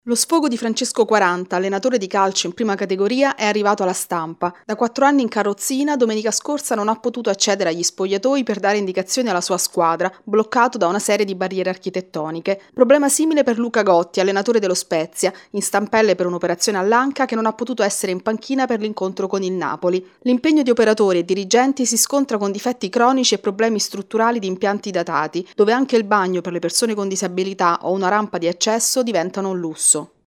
Nello sport italiano tante campionesse ma poche manager. Il servizio